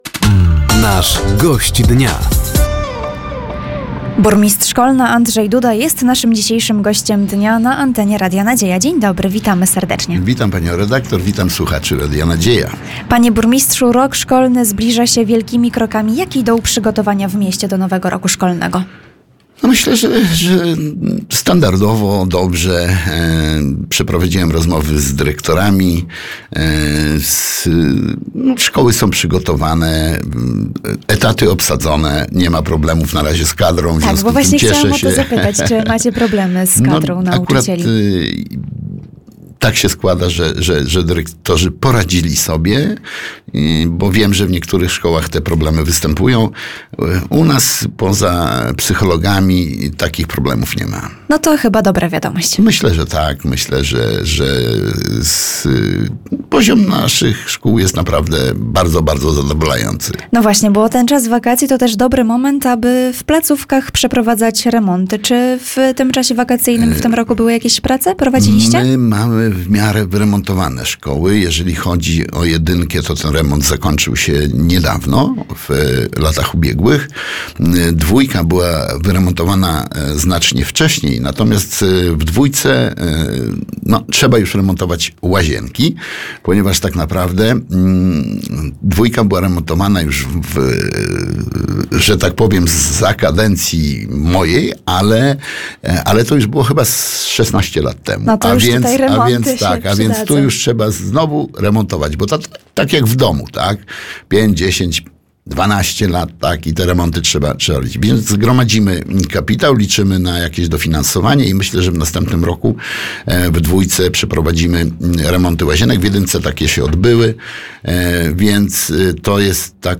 Przygotowania do nowego roku szkolnego, podsumowanie akcji wakacyjnych, oferta kulturalna KOKIS, a także plany powstania strzelnicy w mieście – to tematy rozmowy podczas audycji ,,Gość Dnia”. Studio Radia Nadzieja odwiedził Andrzej Duda, burmistrz Kolna.